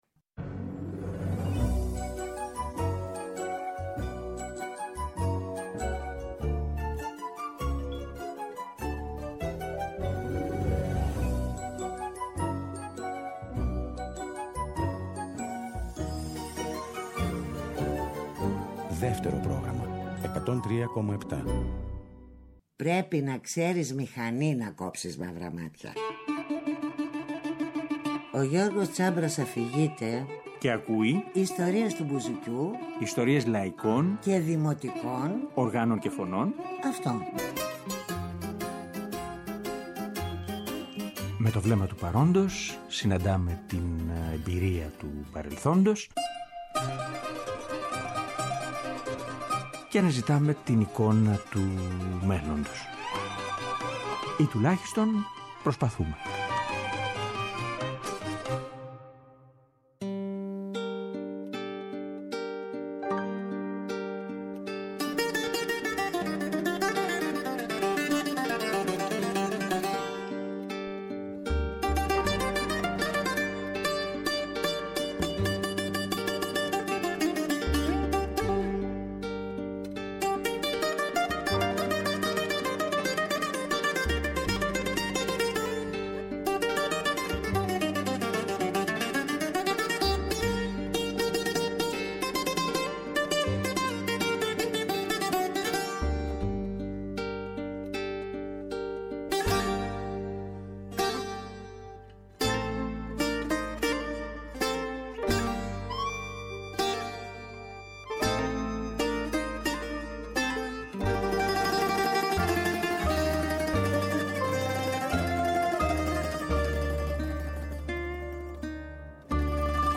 Σήμερα και αύριο λοιπόν, θα ακούσουμε όλοι μαζί κάποια από τα καινούργια του τραγούδια αλλά και κάποια από τα παλιότερα – όσα αγαπάμε κι ακούμε πάντα κι όσα ψάχνουμε ή θέλουμε να ξαναπροσέξουμε.